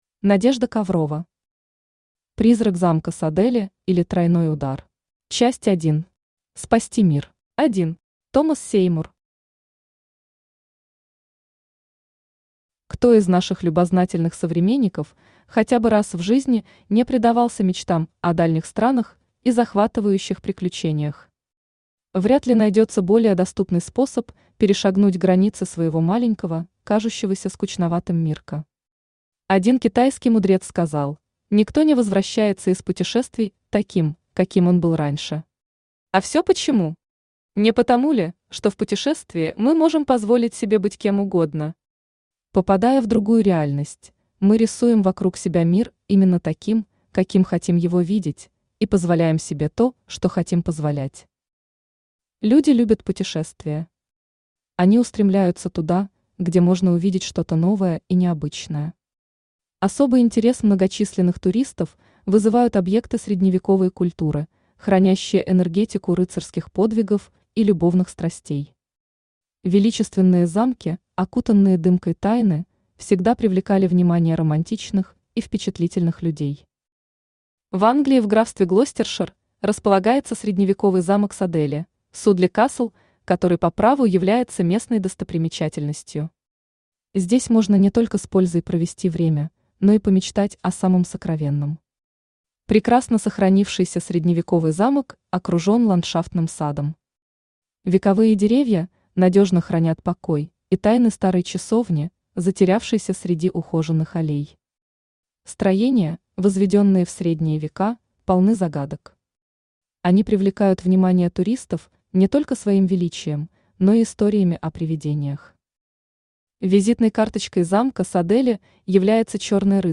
Аудиокнига Призрак замка Садели, или Тройной удар | Библиотека аудиокниг
Aудиокнига Призрак замка Садели, или Тройной удар Автор Надежда Коврова Читает аудиокнигу Авточтец ЛитРес.